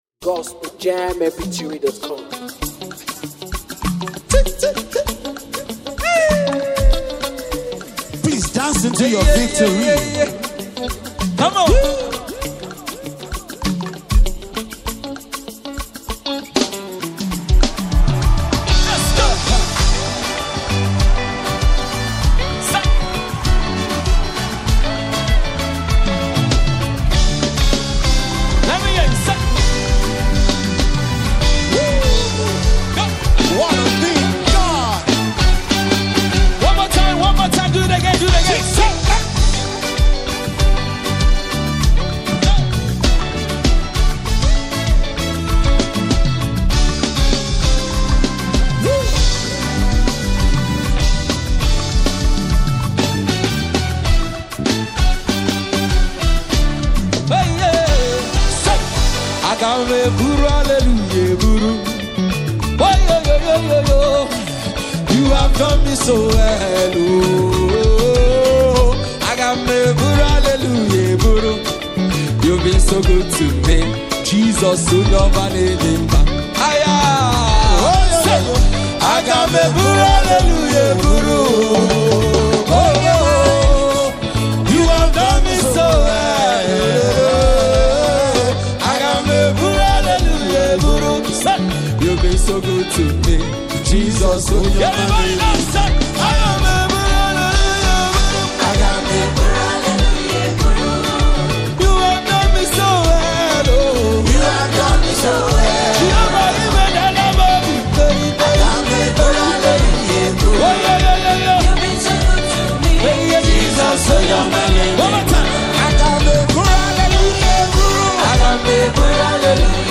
This uplifting and soulful track is sure to be a hit!.